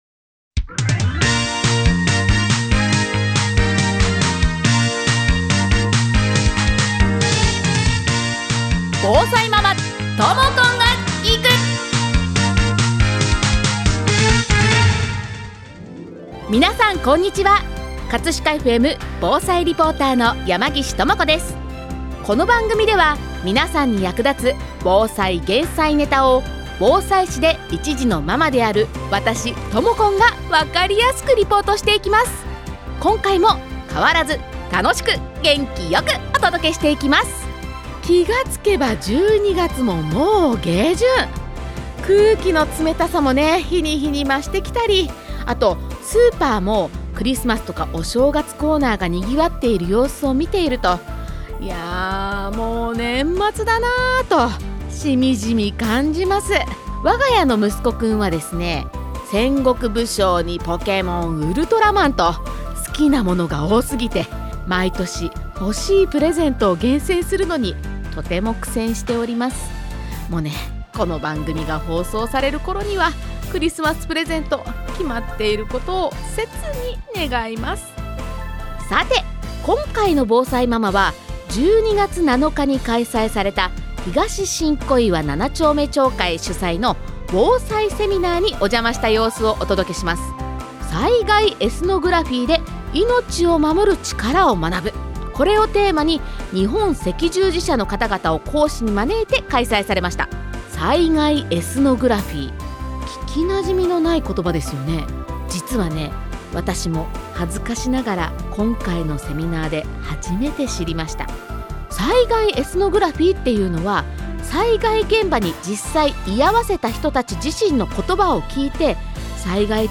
今回は12月7日に開催された、東新小岩7丁目町会主催の「防災セミナー」にお邪魔しました！